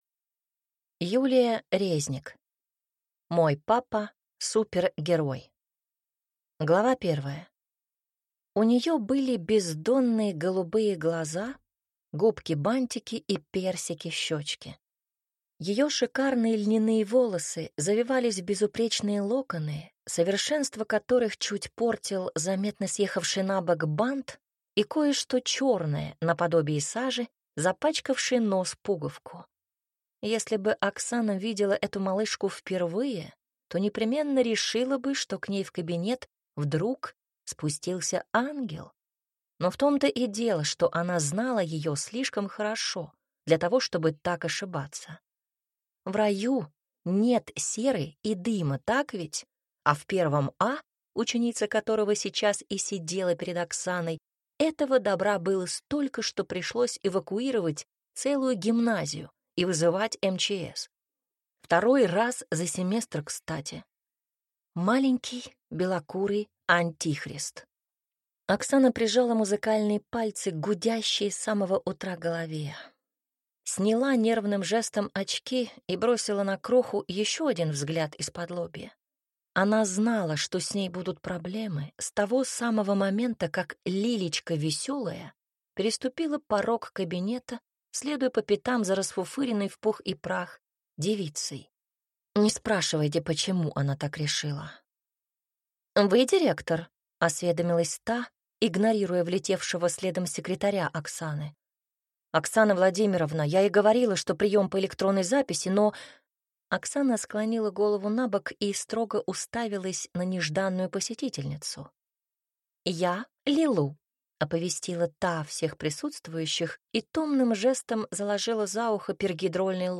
Аудиокнига Мой папа – супергерой | Библиотека аудиокниг